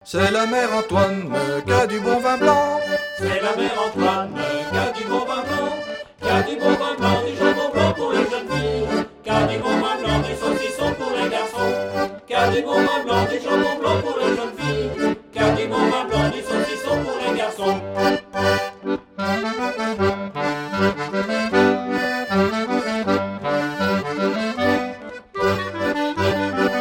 Chants brefs - A danser
danse : paligourdine
Pièce musicale éditée